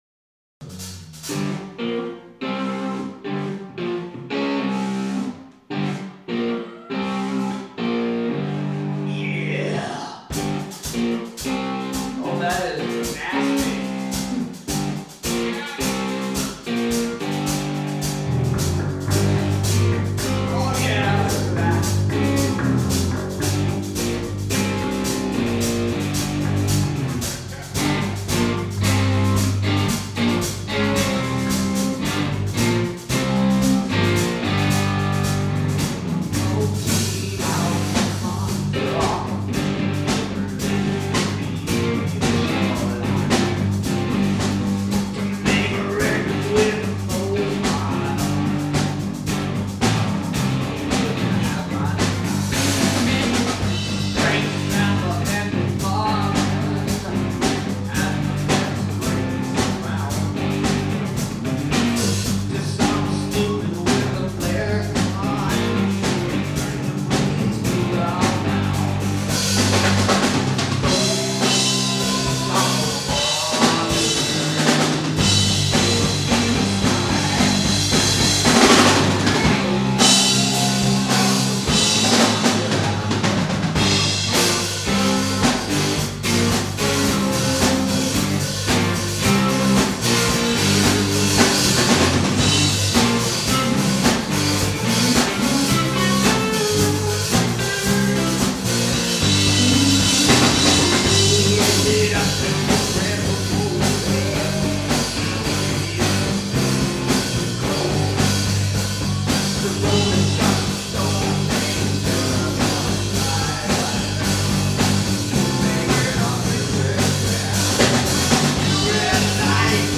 A bunch of friends got together again to make some noise.